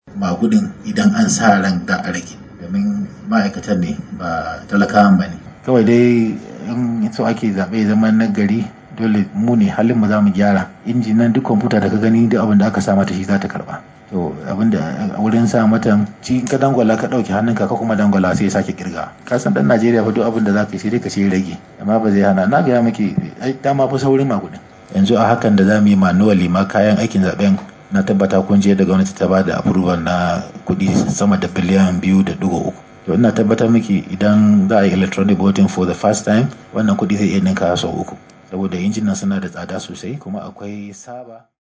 Farfesa Garba Ibrahim Sheka, shine shugaban Hukumar ta jihar Kano, ya ce tsarin naurar zamani, ba shine zai kawo sauyi ko kuma magance matsalar magudi ba.
Saurarin karin bayaninsa a kasa;